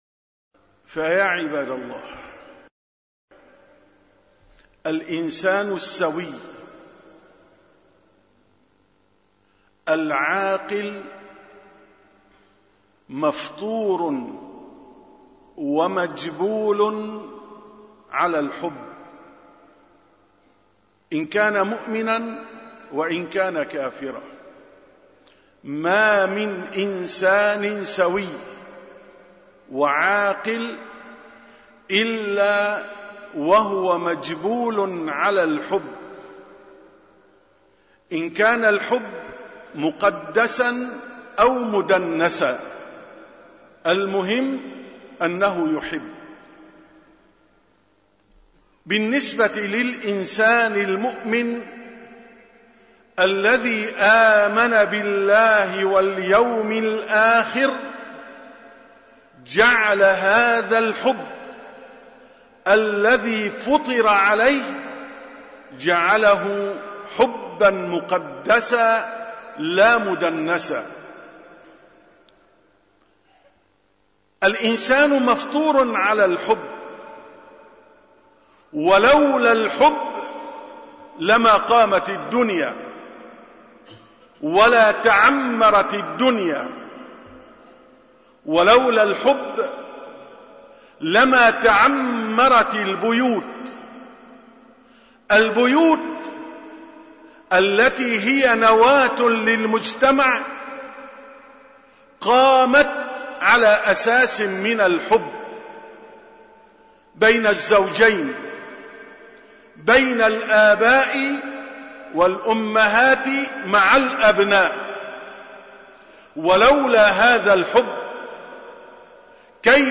931ـ خطبة الجمعة: الدافع إلى محبة سيدنا رسول الله صلى الله عليه وسلم